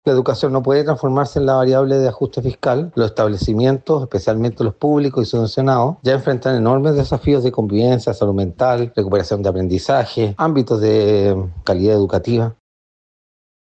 Sin embargo, a pesar de las explicaciones de la cartera, el diputado del Partido Socialista, Arturo Barrios, manifestó su descontento con la situación, asegurando que la educación no puede transformarse en la variable de ajuste fiscal del Estado.